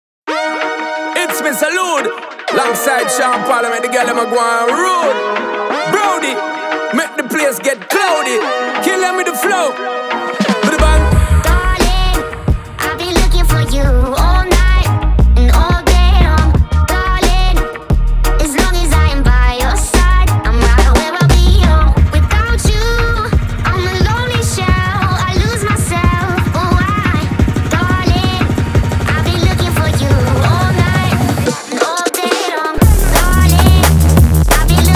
Dance Jungle Drum'n'bass
Жанр: Танцевальные